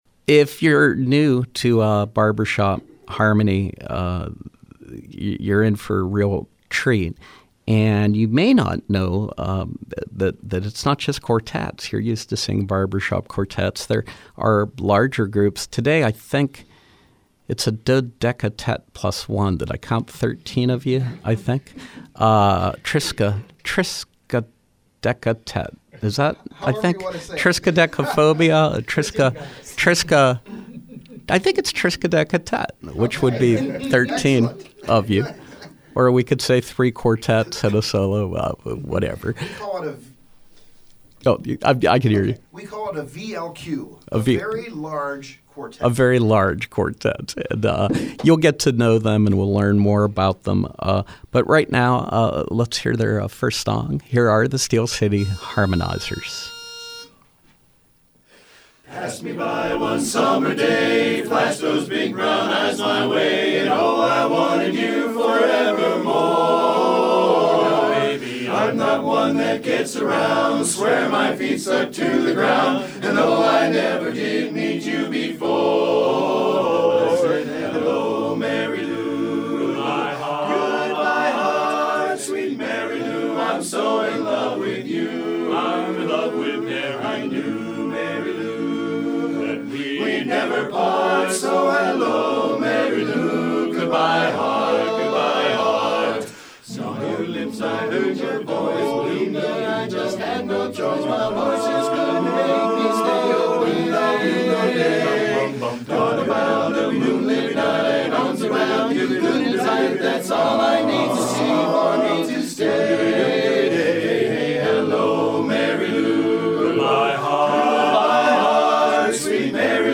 Live Music: Steel City Harmonizers
From 03/26/2016: Members of the Steel City Harmonizers perform barbershop tunes in advance of the Speakeasy! benefit dinner, 5/7 at St. Catherine of Sweden, Allison Park.